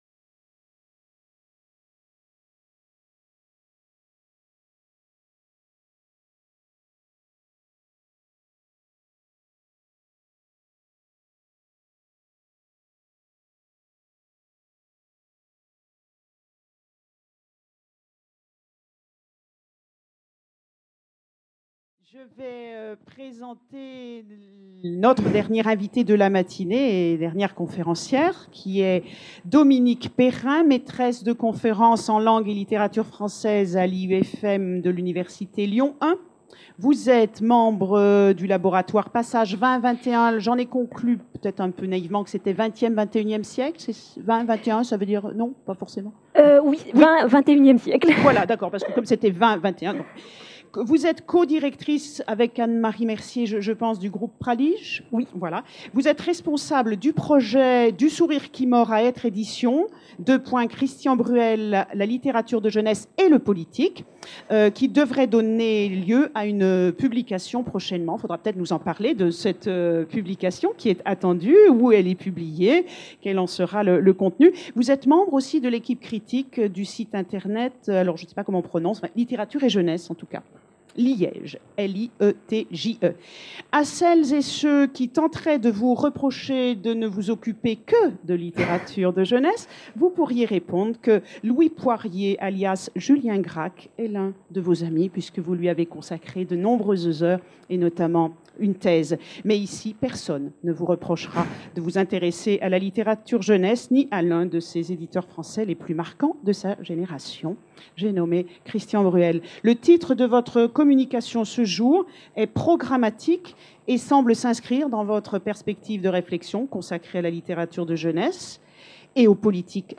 Journée d’étude dédiée à Christian Bruel - Christian Bruel, les ressorts du collectif : créer, éditer, lire à plusieurs | Canal U